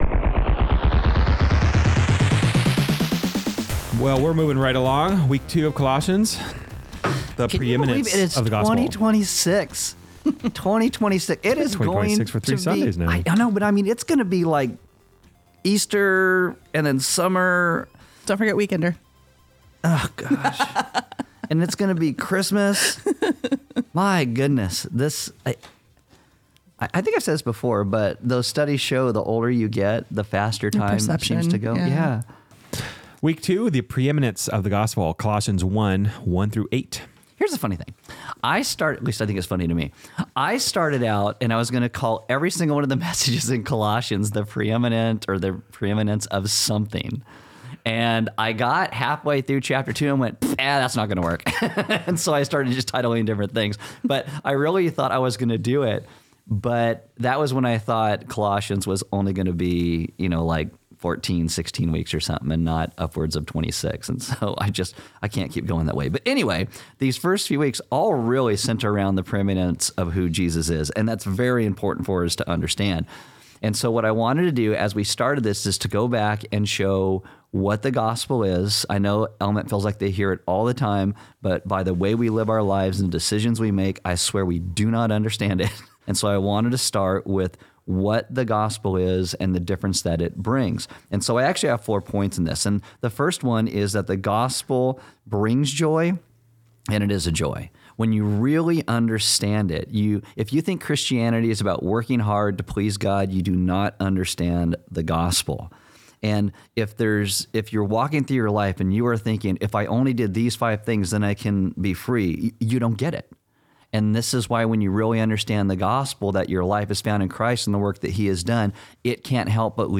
Each week we will be putting out a short discussion podcast that has some tidbit for you. It could be logistical, some will follow along with the series and help prepare you for the upcoming message, or simply give you a behind the scenes look at our discussions so you know what we are doing what we are doing.